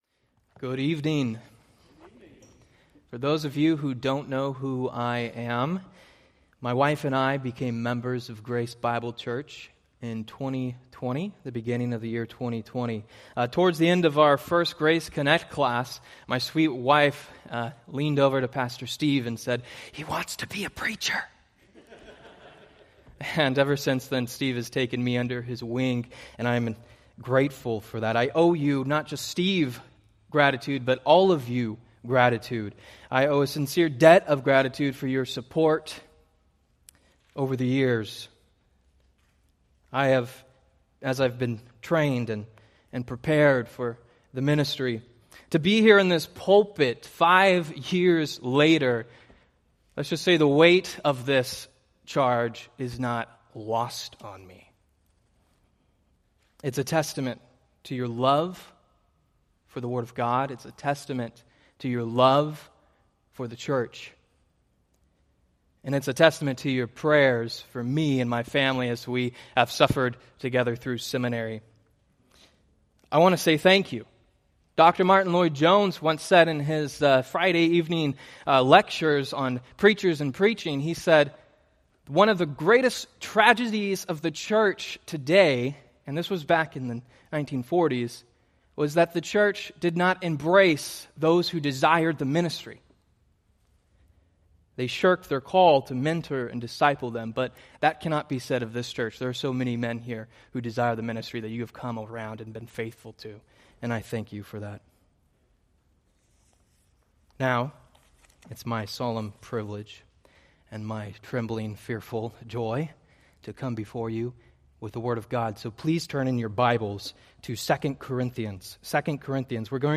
Sermon Details